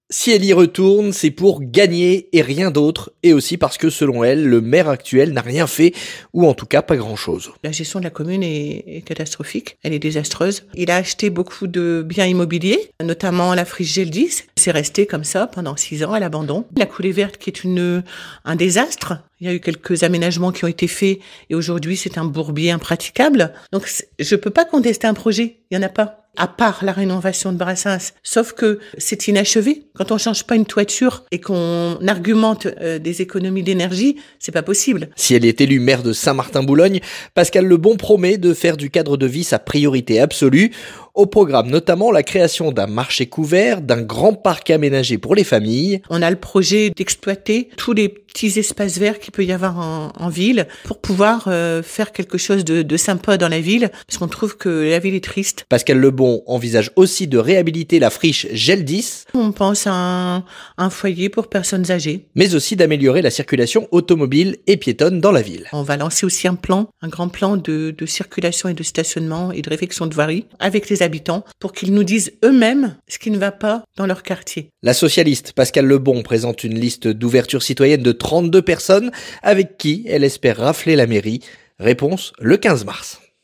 ENTRETIEN - A Saint-Martin-Boulogne, Pascale Lebon dénonce une "gestion désastreuse" et dévoile ses projets